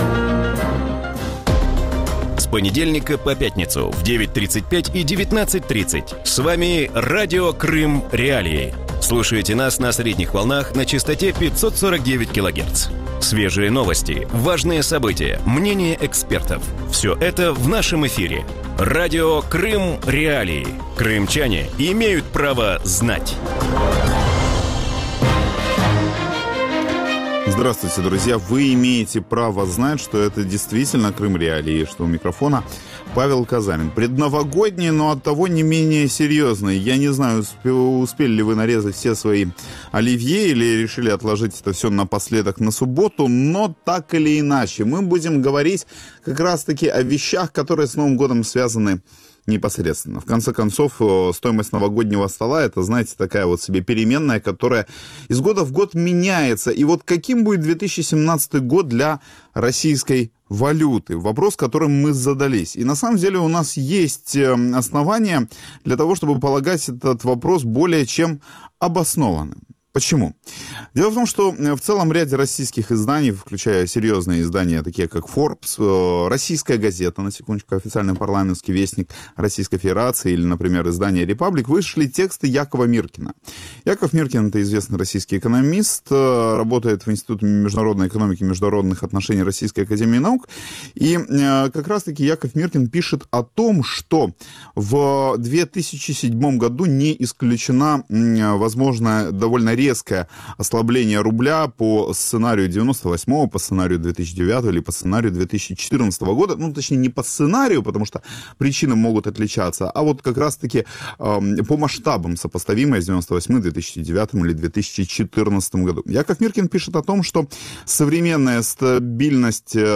В вечернем эфире Радио Крым.Реалии обсуждают состояние и перспективы российской валюты. Почему укрепляется российский рубль, долго ли он продержится на нынешней отметке и какие у него перспективы в 2017 году?